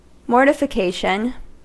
Uttal
Synonymer chagrin Uttal US Ordet hittades på dessa språk: engelska Ingen översättning hittades i den valda målspråket.